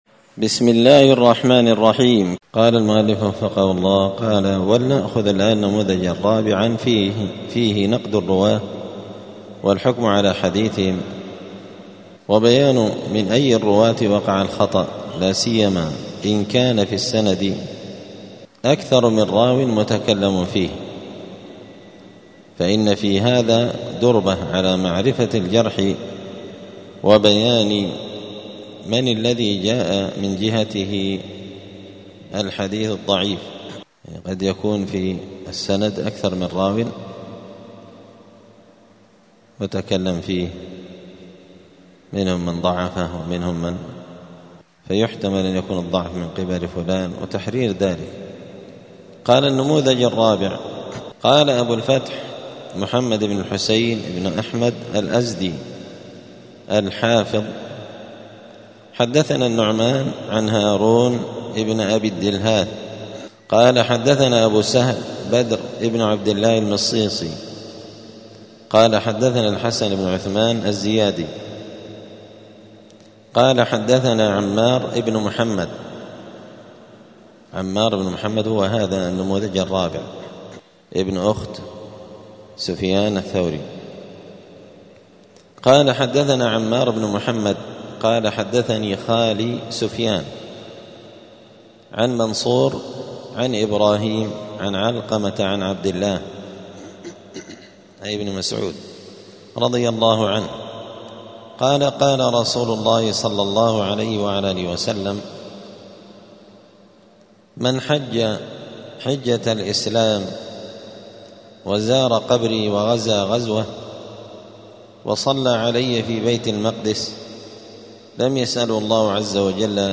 دار الحديث السلفية بمسجد الفرقان قشن المهرة اليمن
الجمعة 29 شعبان 1446 هــــ | الدروس، المحرر في الجرح والتعديل، دروس الحديث وعلومه | شارك بتعليقك | 32 المشاهدات